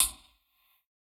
UHH_ElectroHatA_Hit-24.wav